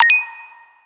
notification.mp3